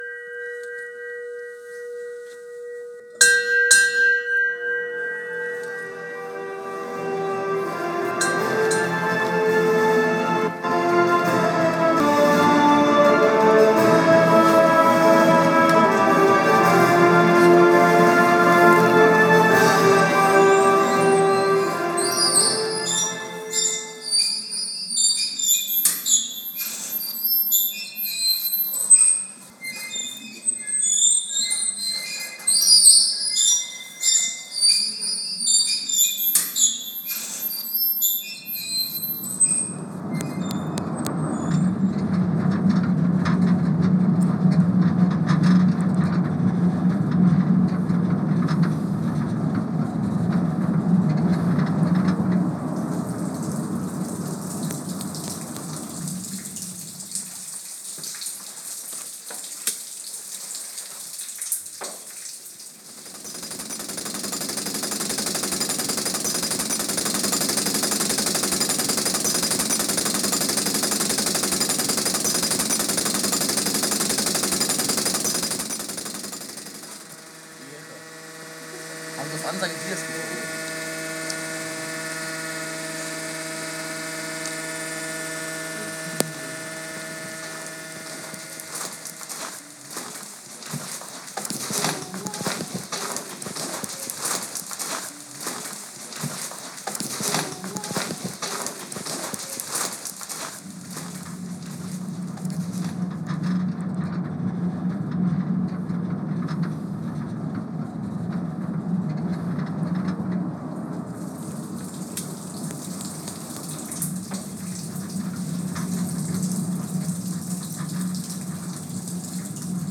Soundscapes & Kompositionen
Schülerworkshop im Projekt „Barsinghausen-klingt“. An besondren Orten der Stadt werden Tonaufnahmen gemacht, […]
Ausflug-bergwerk-1-1.mp3